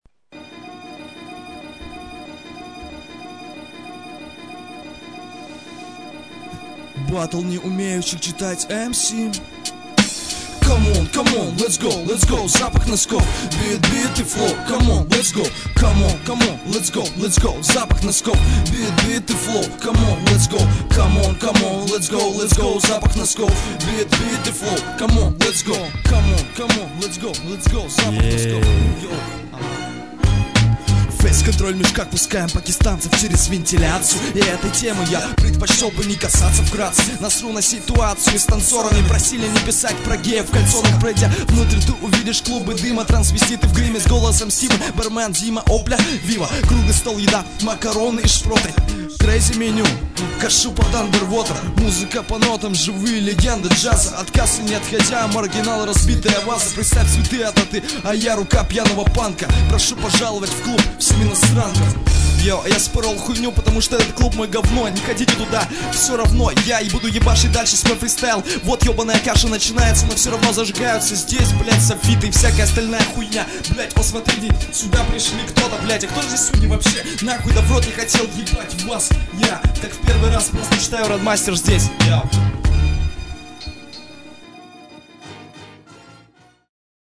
• Æàíð: Ðýï